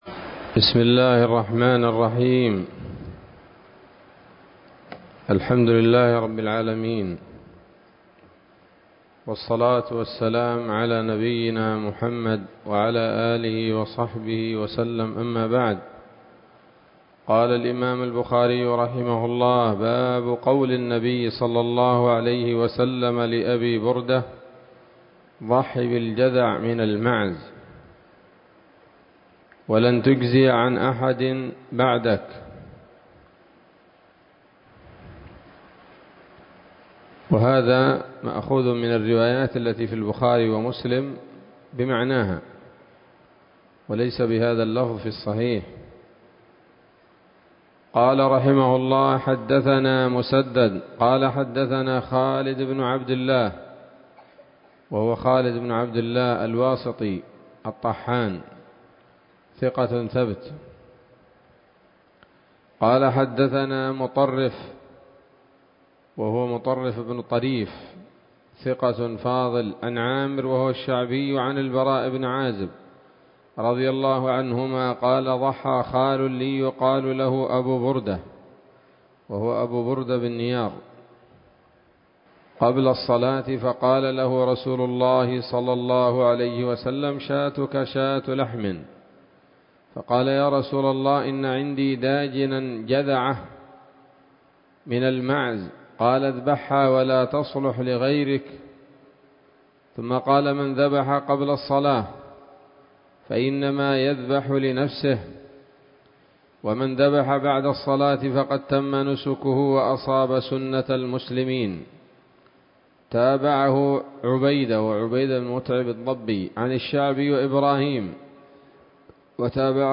الدرس الثامن من كتاب الأضاحي من صحيح الإمام البخاري